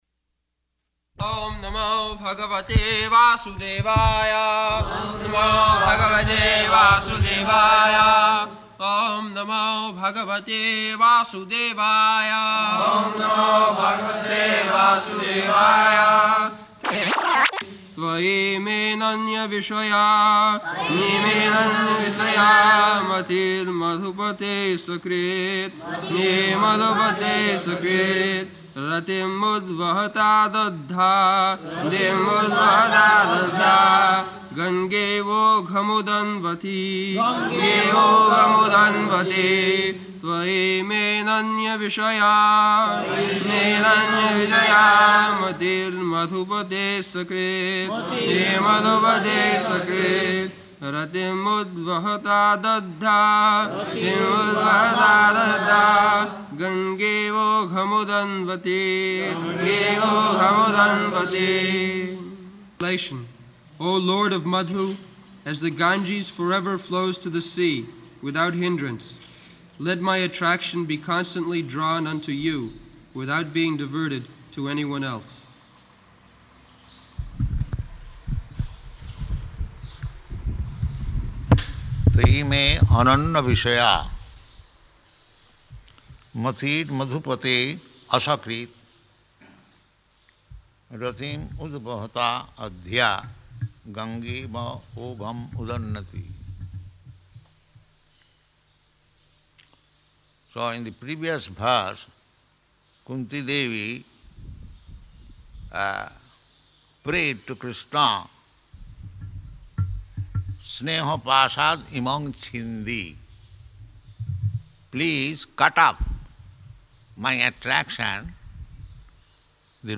October 22nd 1974 Location: Māyāpur Audio file
[devotees repeat] [leads chanting of verse, etc.]